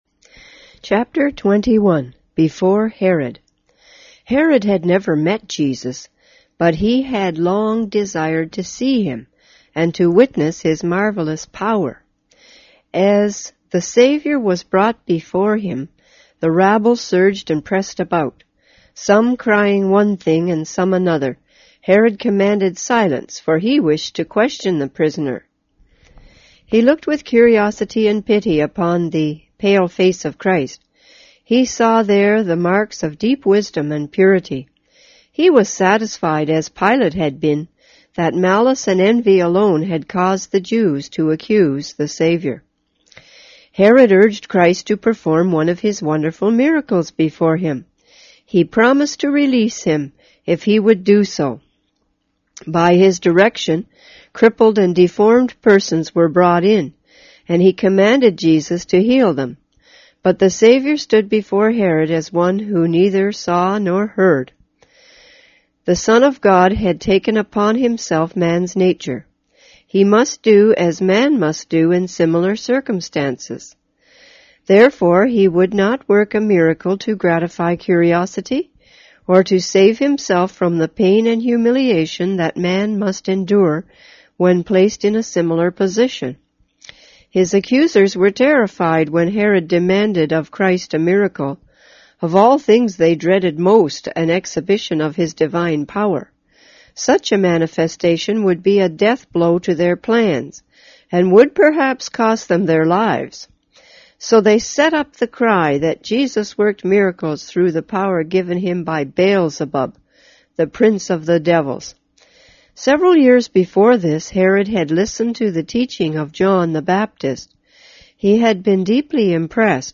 on 2010-06-03 - Ellen G. Whyte Books on Audio